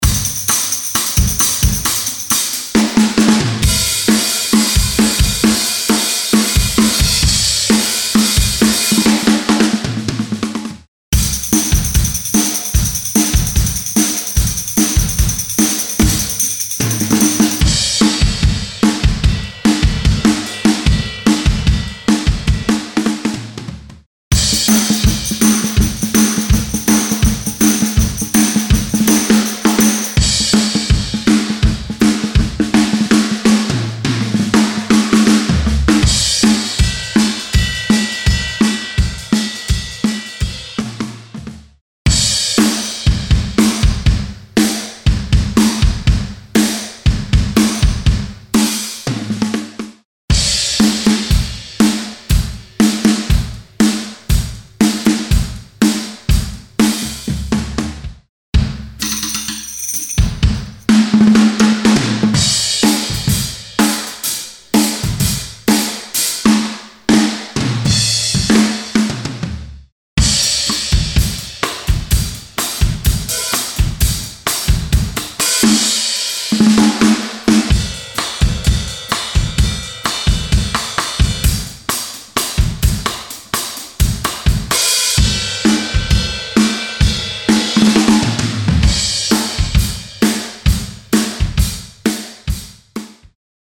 Boasting almost 2 Gigabytes in size with a total of 450 live acoustic drum loops, this collection of pure soul grooves takes you through the Motown era of classic and retro soul rhythms.
Straight from the heart of vintage Motown beats with tambourine, build-ups, breakdowns and trademark drum fills from a bygone era.
Tempos: 70 to 164 Bpm
retro_soul_demomix.mp3